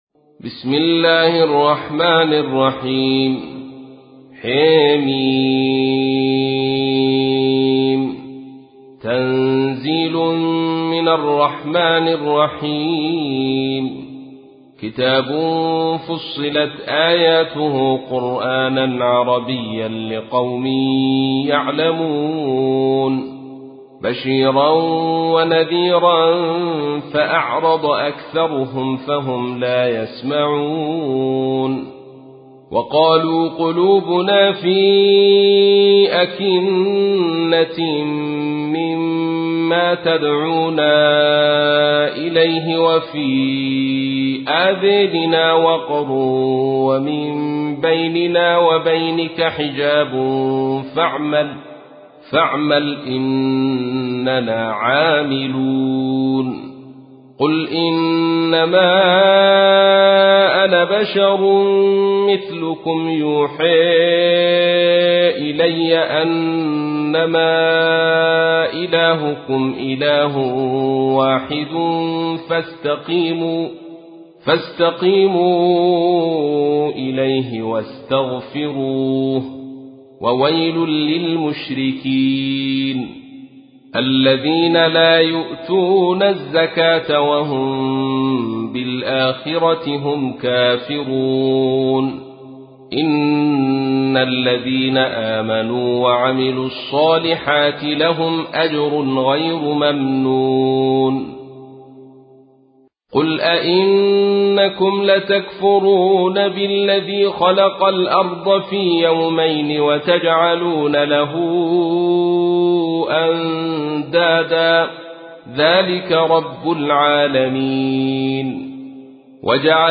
تحميل : 41. سورة فصلت / القارئ عبد الرشيد صوفي / القرآن الكريم / موقع يا حسين